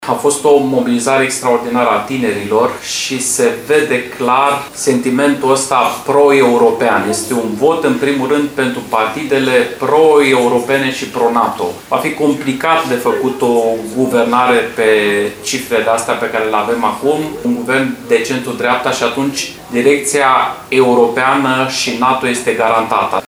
Și tot după ora 21, la sediul USR Suceava, am stat de vorbă cu deputatul EMANUEL UNGUREANU: